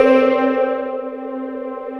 SAILOR M C4.wav